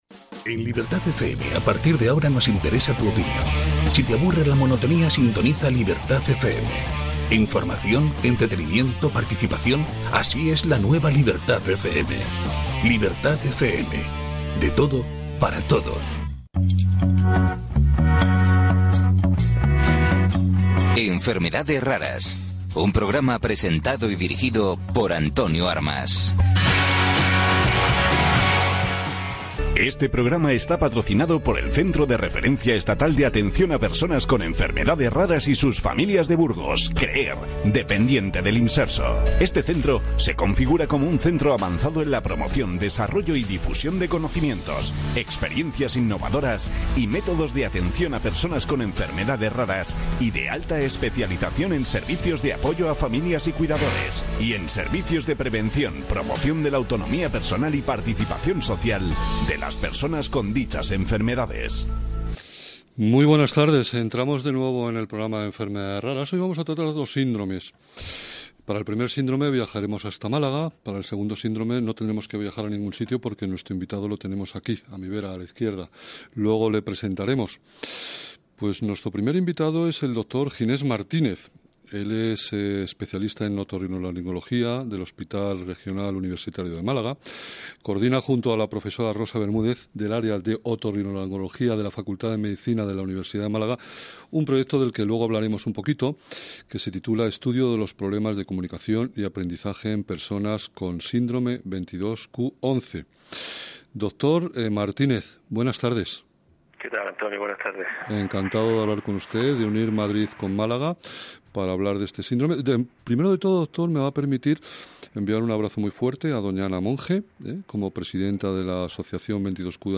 ENTREVISTAS - Asociación Síndrome 22q11.2 Andalucía